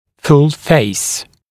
[ful feɪs][фул фэйс]полное лицо, все лицо